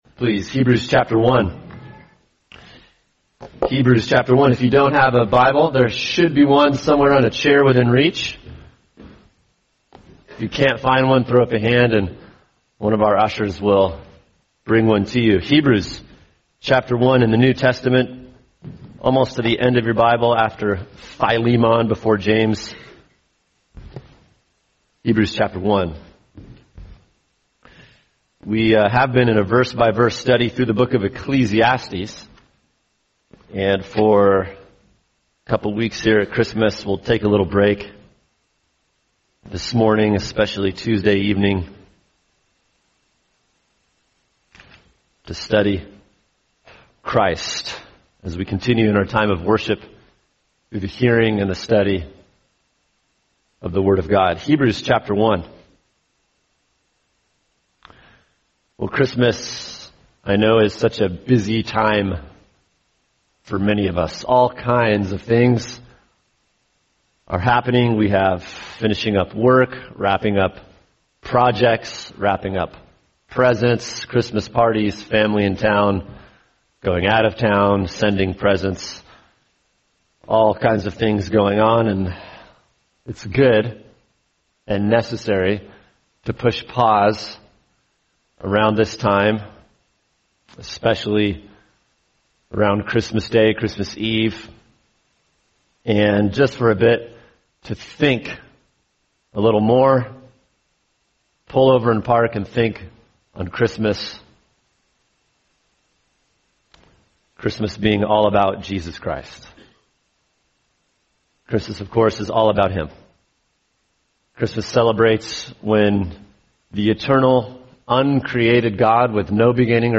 [sermon] Hebrews 1:1-4 Christmas and the Supremacy of Jesus Christ | Cornerstone Church - Jackson Hole